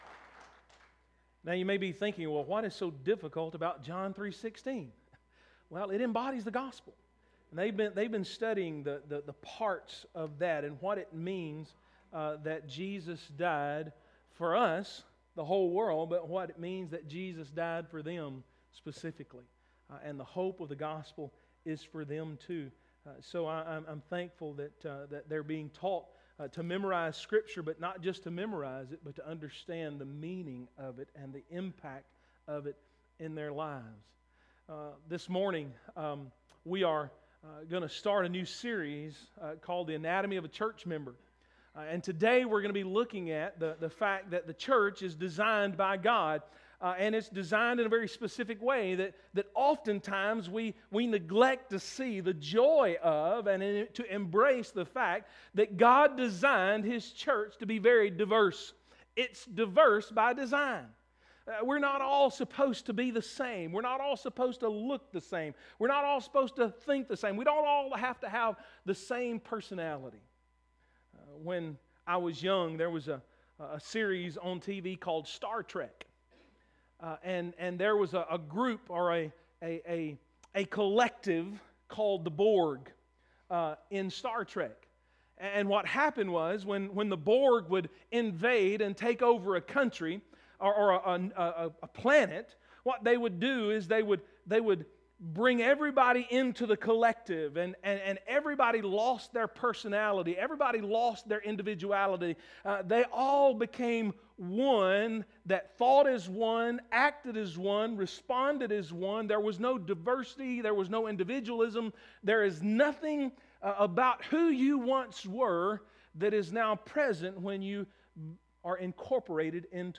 A teaching on Church Membership.